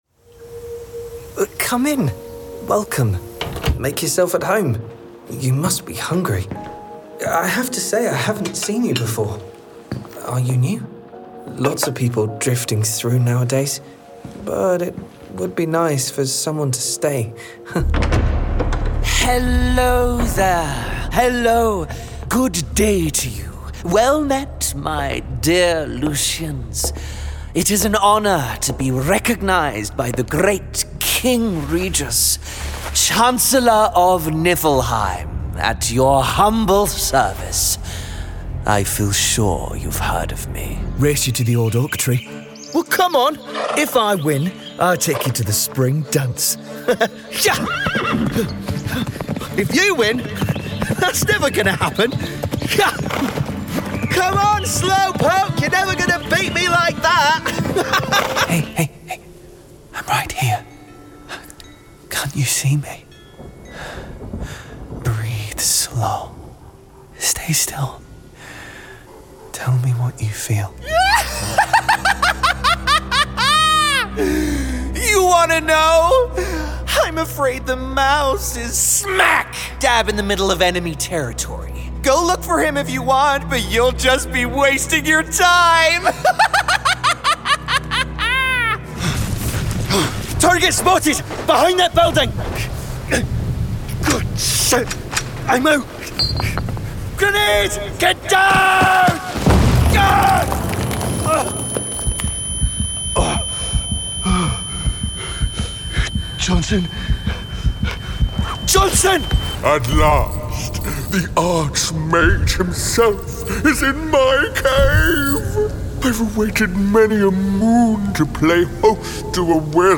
Video Game Showreel
His approachable, youthful British RP voice has roots in Yorkshire, East Midlands, and Essex.
Male
Neutral British
Confident
Playful